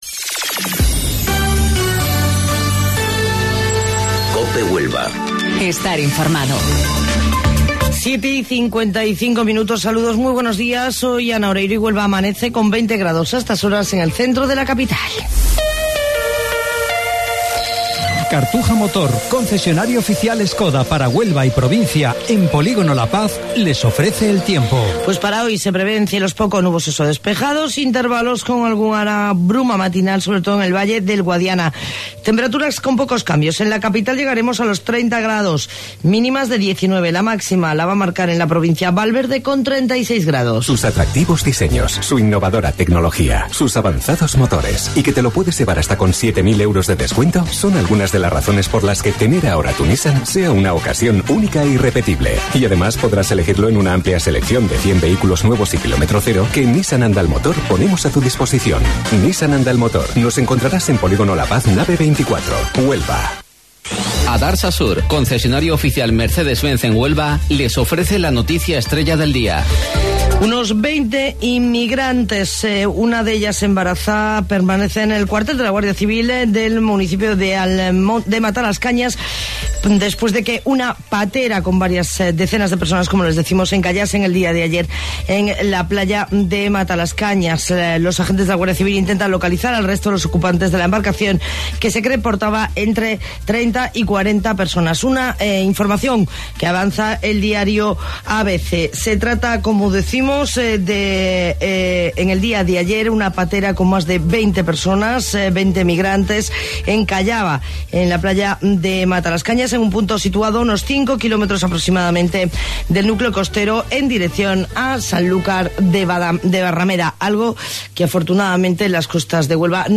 AUDIO: Informativo Local 07:55 del 15 de Julio